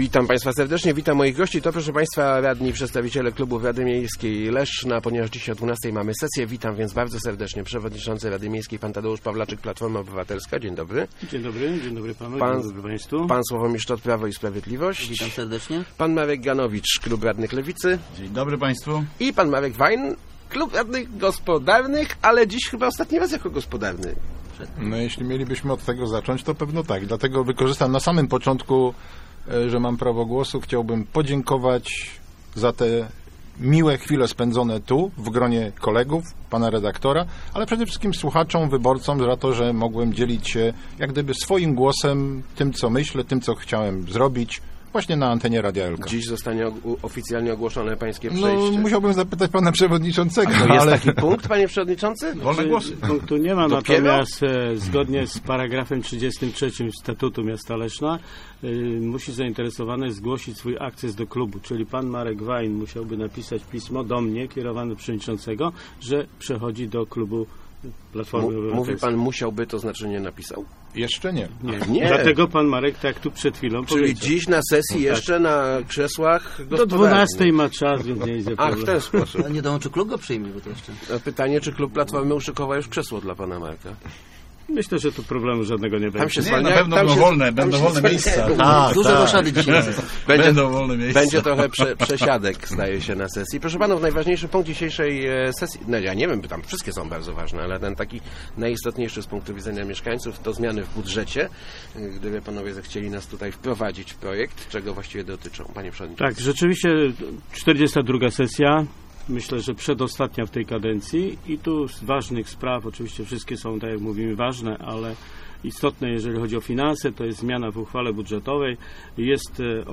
Podział nadprogramowych siedmiu milionów złotych będzie jednym z głównych punktów programu dzisiejszej sesji Rady Miejskiej Leszna. Goszczący w Rozmowach Elki przedstawiciele klubów byli zgodni, że pięć milionów należy przeznaczyć na wcześniejszą spłatę zadłużenia ...